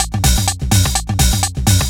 DS 126-BPM A08.wav